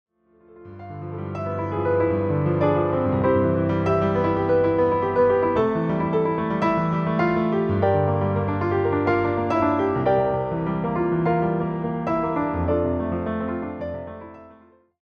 follows with a gentle approach